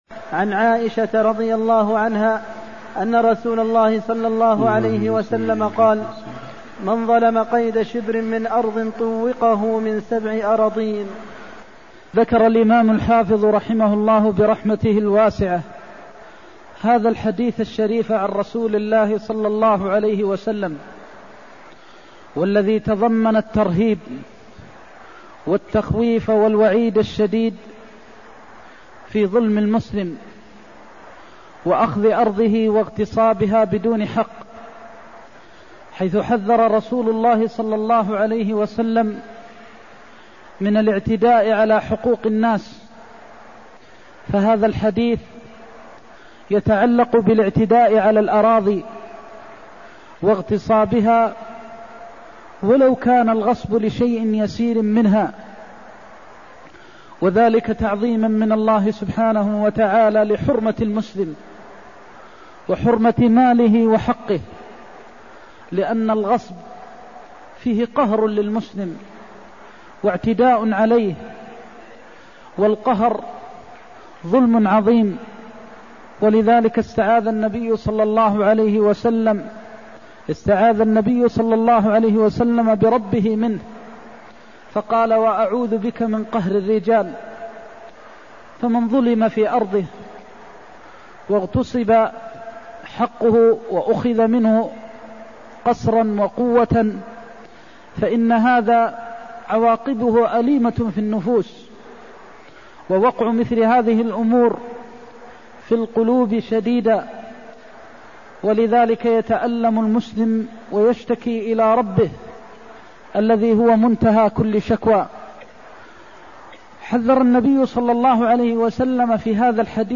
المكان: المسجد النبوي الشيخ: فضيلة الشيخ د. محمد بن محمد المختار فضيلة الشيخ د. محمد بن محمد المختار تحريم الظلم وغصب الأرض (276)